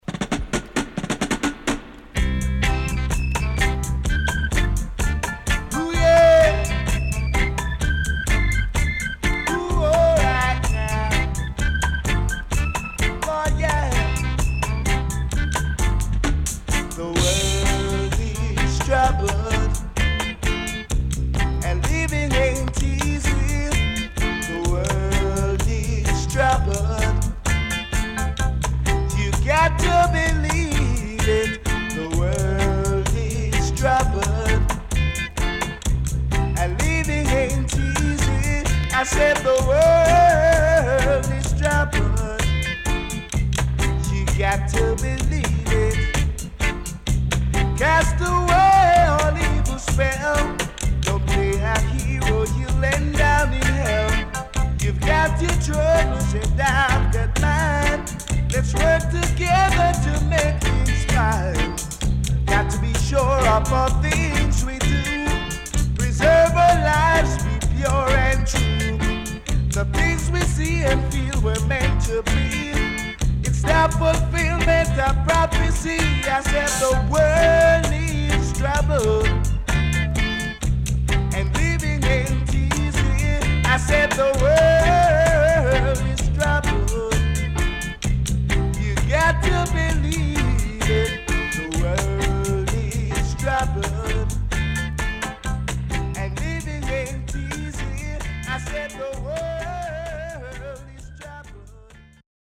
Great Stepper.後半Dubwise接続。W-Side Great
SIDE A:うすいこまかい傷ありますがノイズあまり目立ちません。